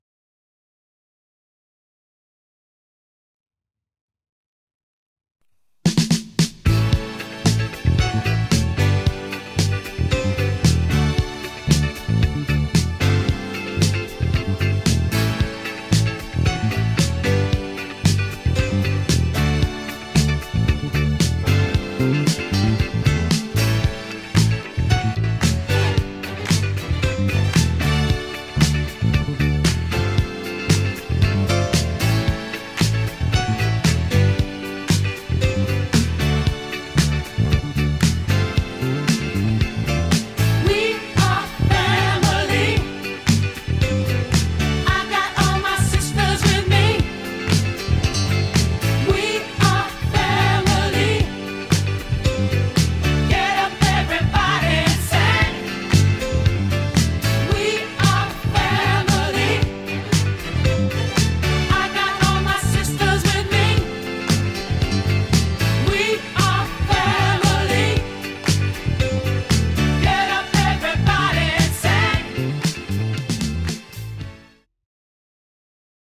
Jedná se Precision z dílny custom shopu v úpravě heavy relic.
Je víc konkrétní, má trochu více výšek, ale zároveň má pevný základ palisandru a jeho kulatost.
Zvukově naprosté dělo, ačkoliv díky hlazenkám není 100% univerzální, jak uslyšíte z nahrávek.